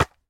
resin_brick_hit1.ogg